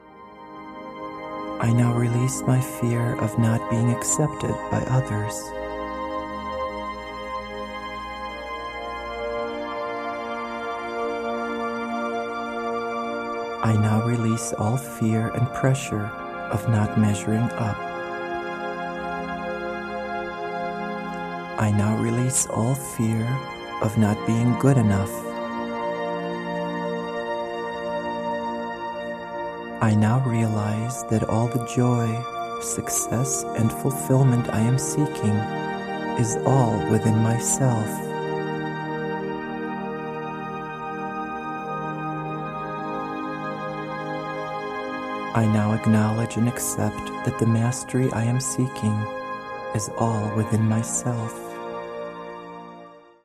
A dynamic digital download from a 2 CD set featuring 2 powerful meditations & visualization exercises designed to open and heal your heart, and to prepare you for your true love.